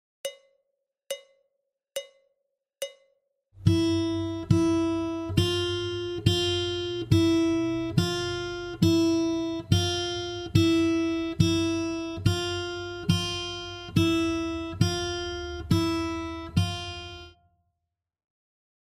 Beispiele sind extra langsam, in anfängergerechtem Tempo eingespielt,
01_WarmUp_F_hoch.mp3